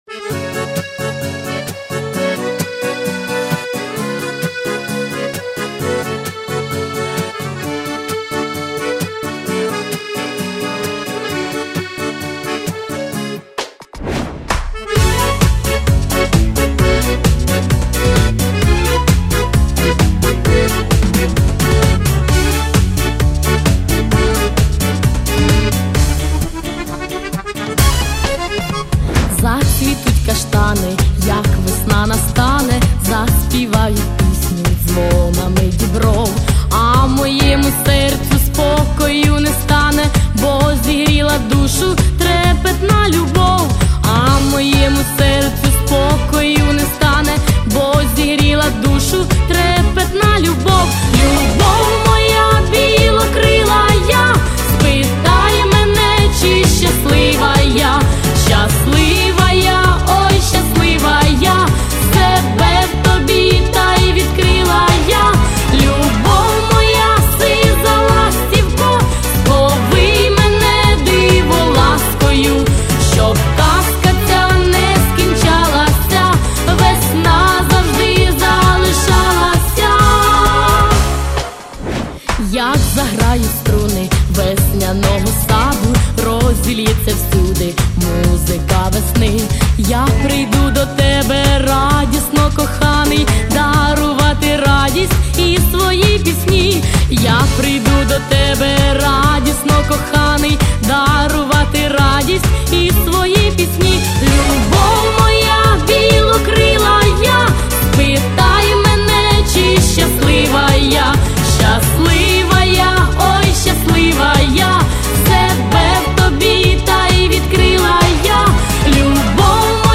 Всі мінусовки жанру Dance
Плюсовий запис